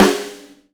• Boomy Snare G# Key 407.wav
Royality free steel snare drum sound tuned to the G# note. Loudest frequency: 1420Hz
boomy-snare-g-sharp-key-407-63D.wav